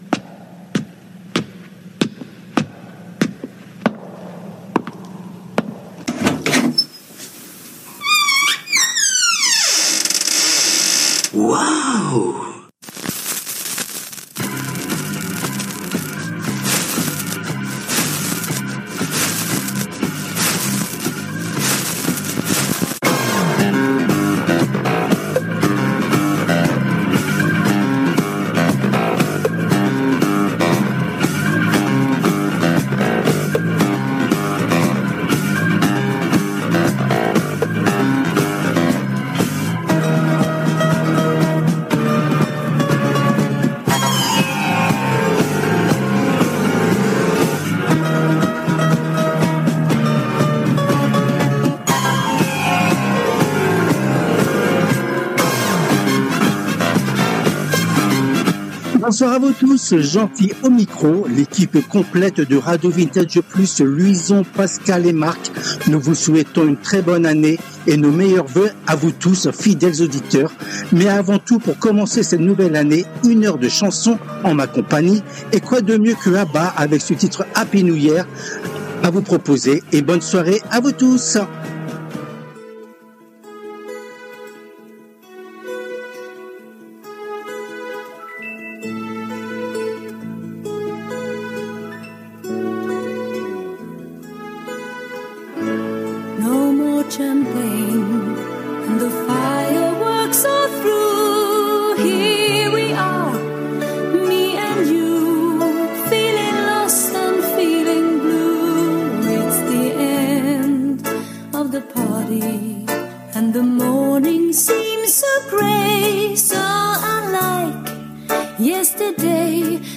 Cette 143ème édition a été diffusée en direct le mardi 06 janvier 2026 à 19h depuis les studios de RADIO RV+ à PARIS .
Les Tubes connus ou oubliés des 60's, 70's et 80's